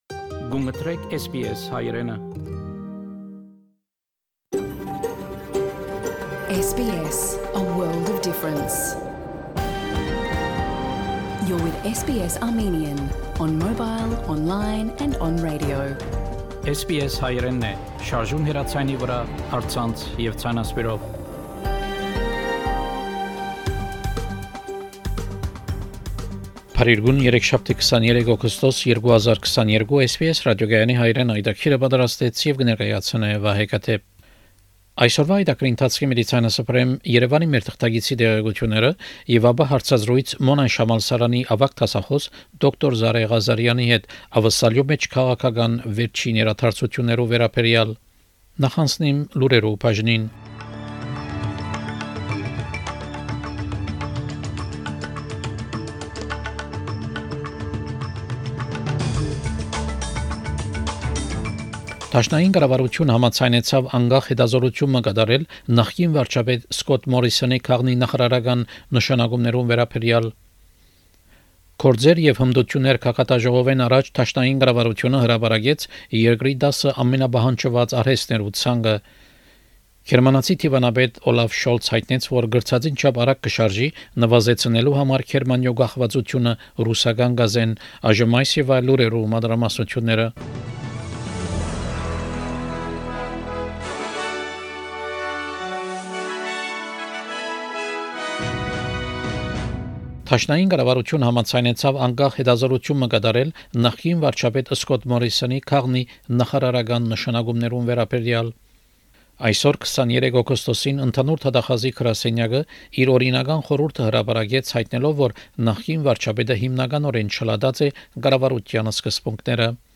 SBS Armenian news bulletin – 23 August 2022
SBS Armenian news bulletin from 23 August 2022 program.